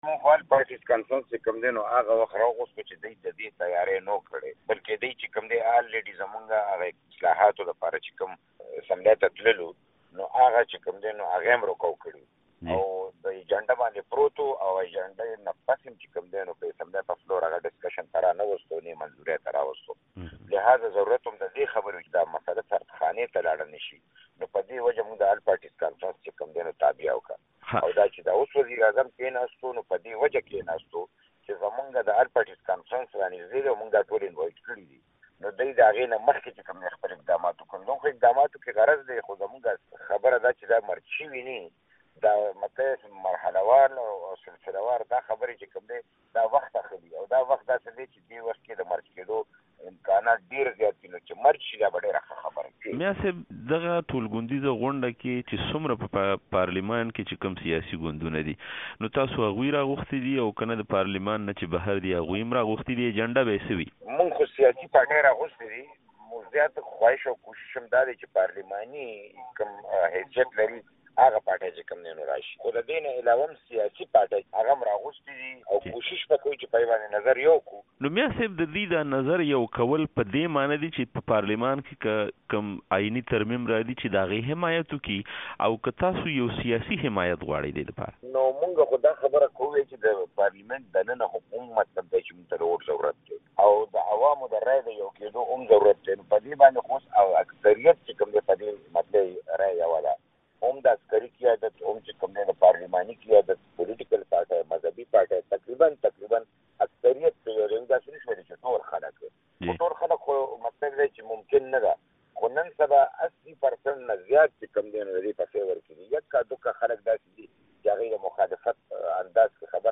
د مشال مرکه